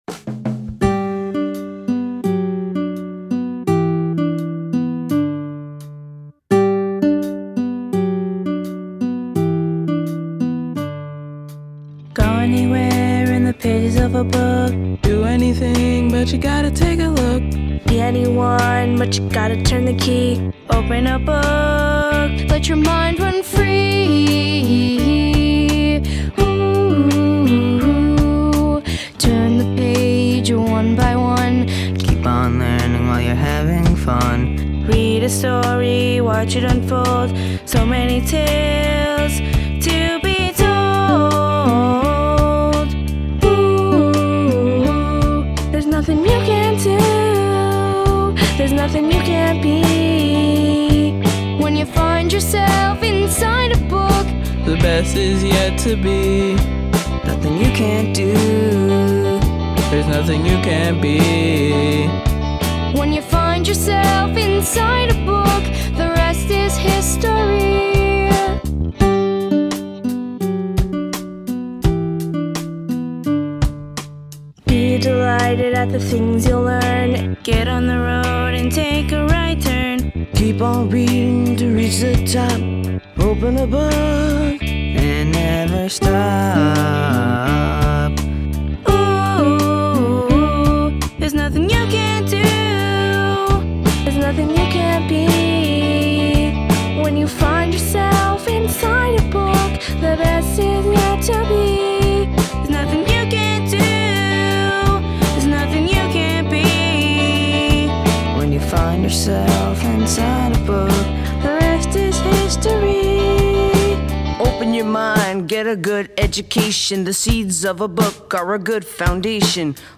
a group of talented Jr/Sr High students